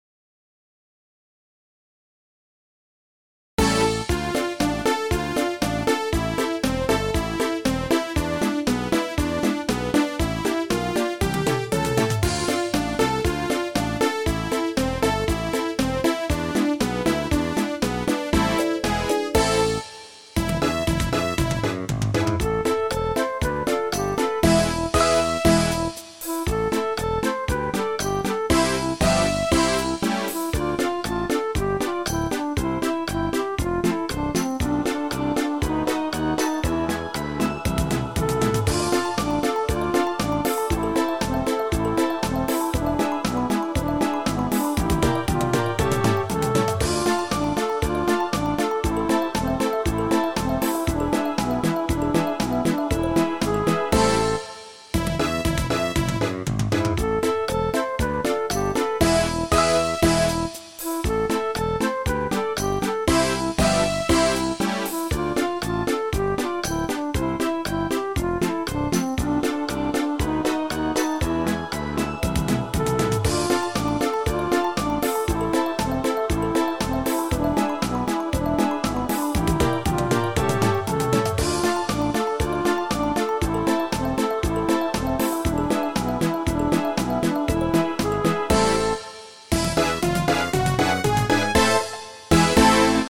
Скачать минус: